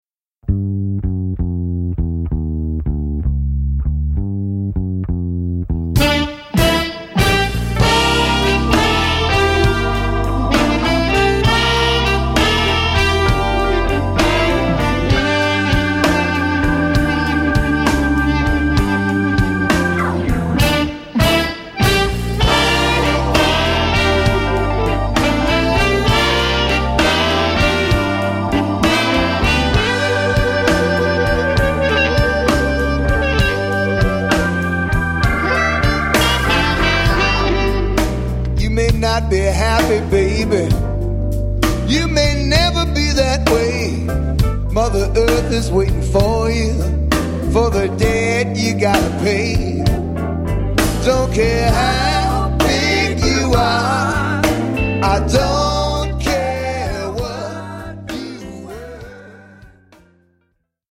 vocal & harmonica